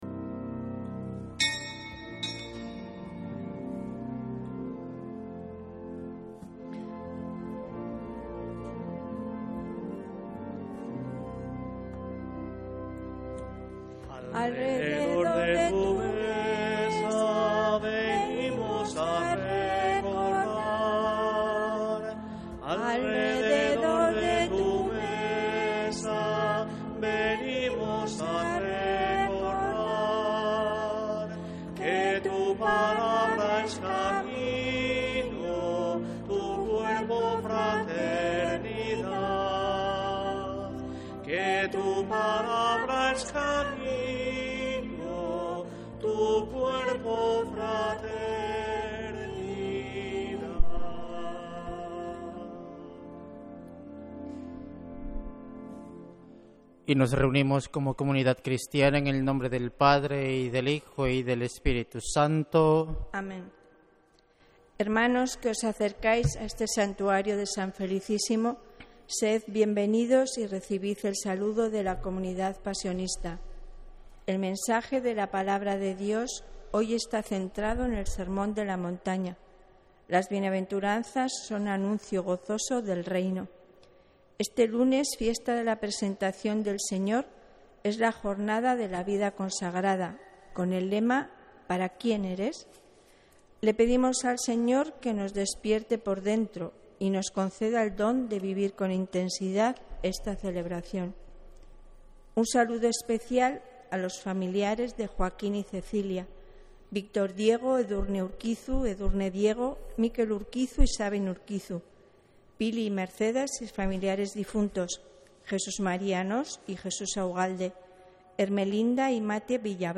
Santa Misa desde San Felicísimo en Deusto, domingo 1 de febrero de 2026